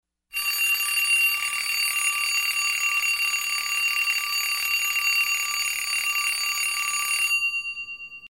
Звук старого механического будильника